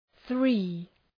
Προφορά
{ɵri:}